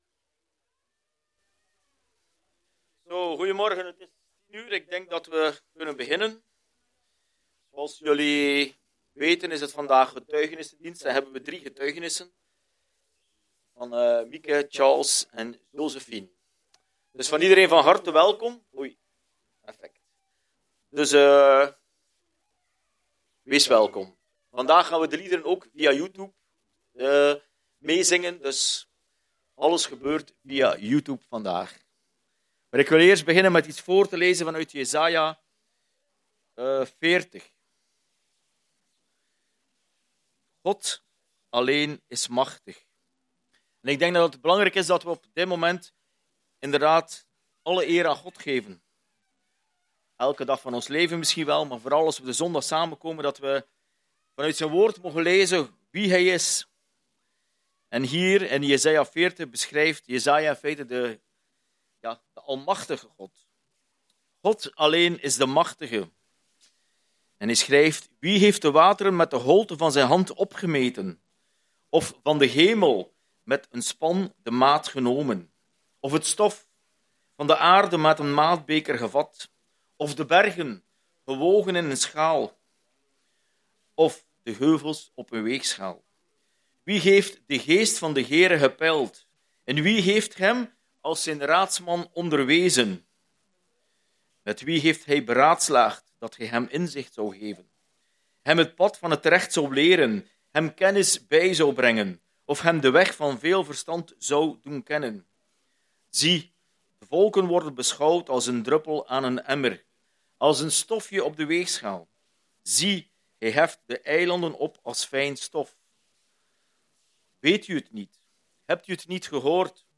GETUIGENISDIENST
29 december 2024 GETUIGENISDIENST Dienstsoort: Getuigenissendienst Versnaperingen voor de ziel Luister Israël !